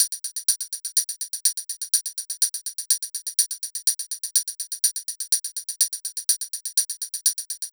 Bleep Hop Tamb Loop.wav